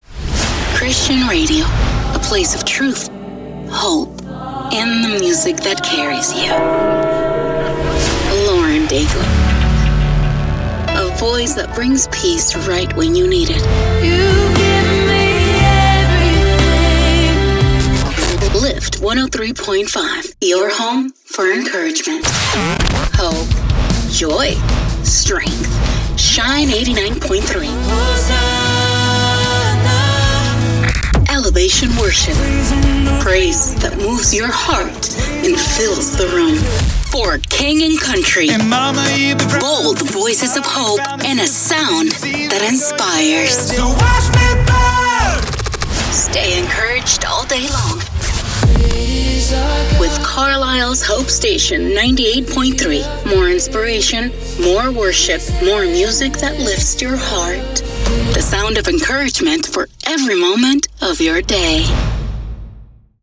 Radio Imaging English Demo
Christian Imaging Demo
Christian-Imaging-Demo.mp3